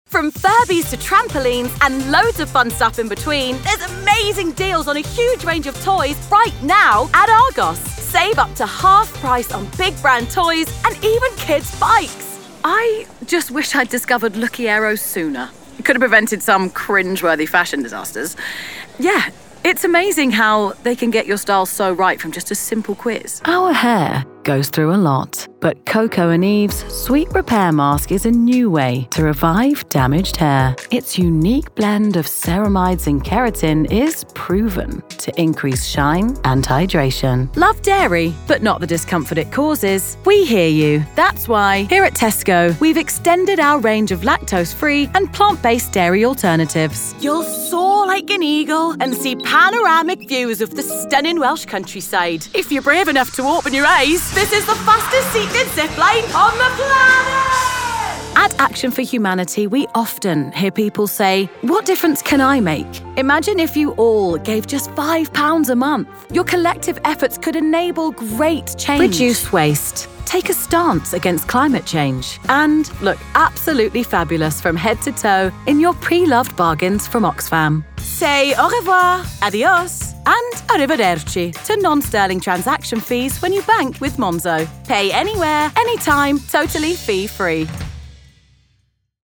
Commercial
London based youthful, husky and chatty female British Voiceover. Relatable, current, and versatile, with both character work and accent work!